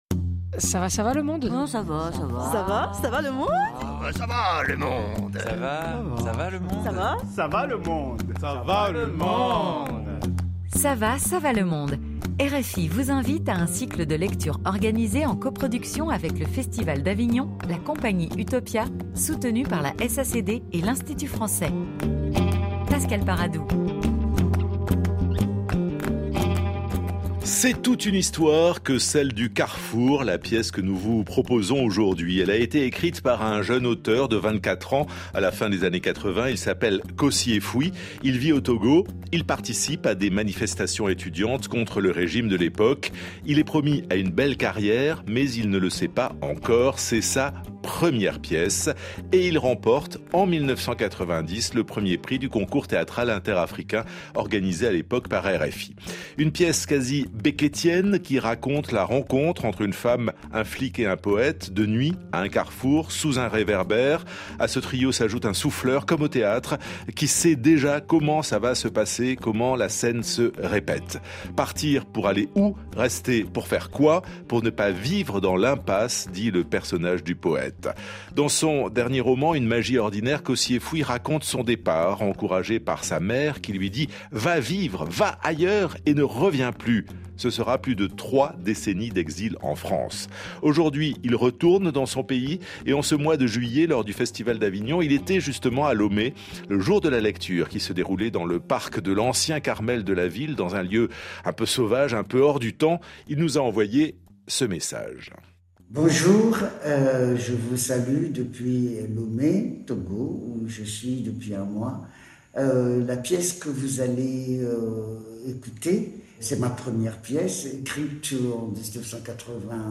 Nous vous proposons la version intégrale de cette lecture.